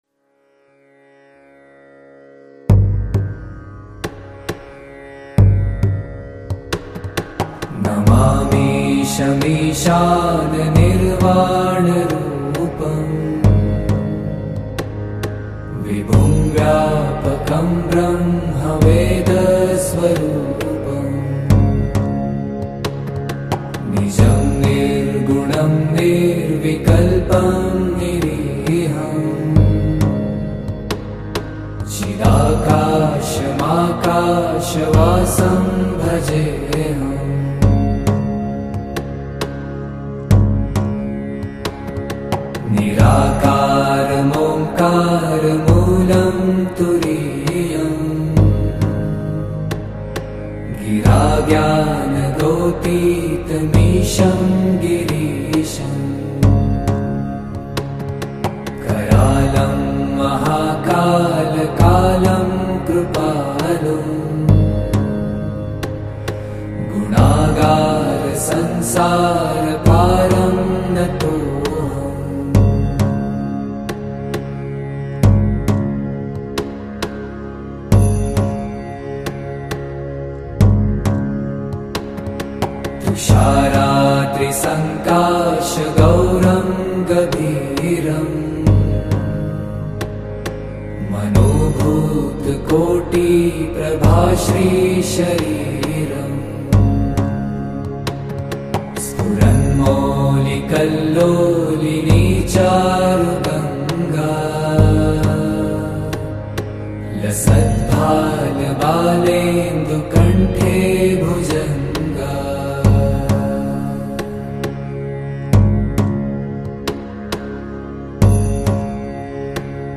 devotional bhajan
Sung in a soothing and powerful voice
Devotional Songs